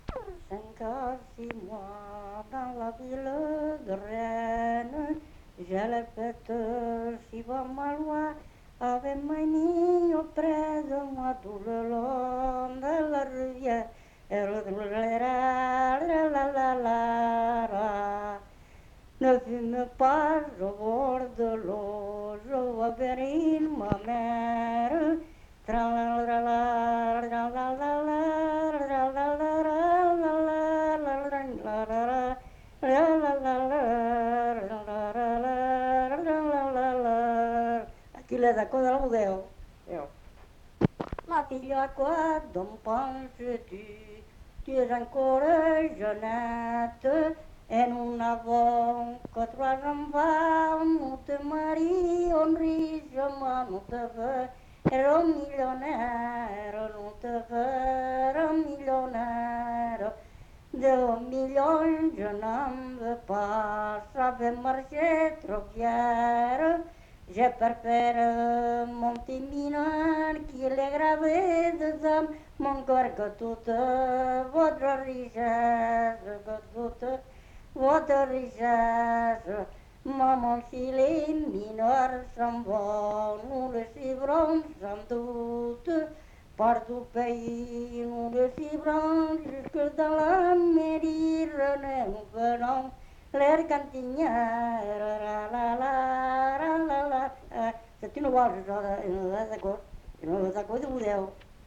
Genre : chant
Type de voix : voix d'homme
Production du son : chanté ; fredonné
Danse : valse
Contextualisation de l'item : air de bodega